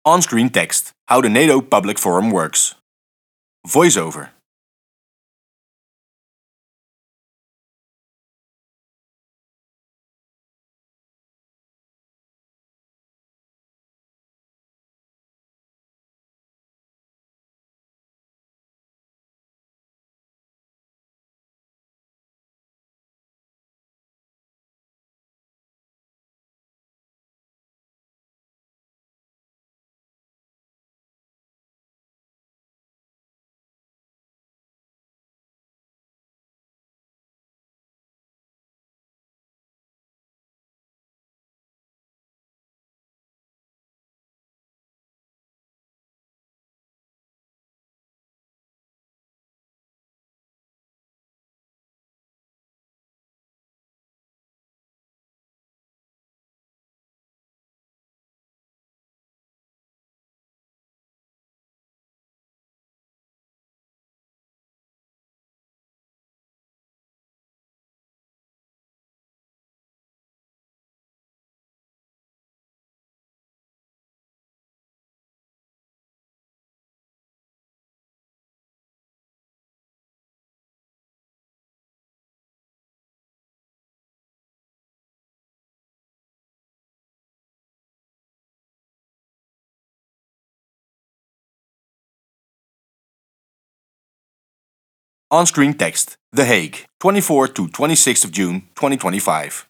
*Chill music plays*